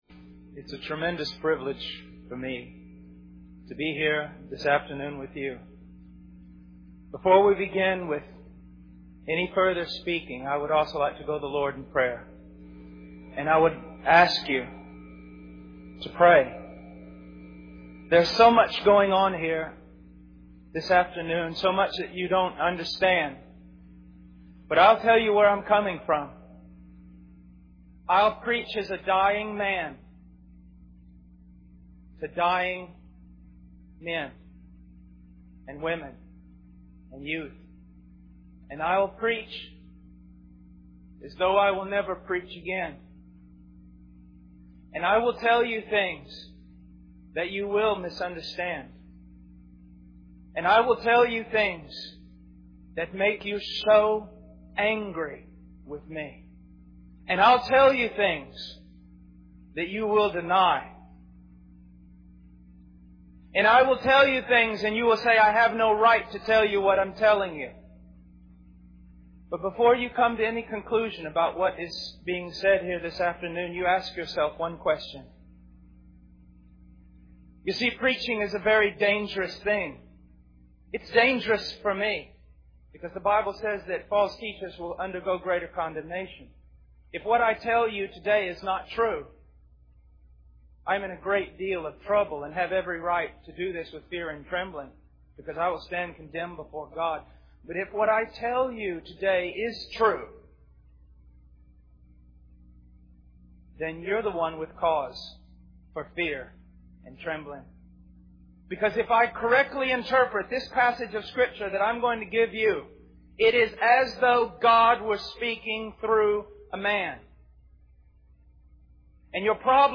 A shocking youth message | Christian Assembly of NSW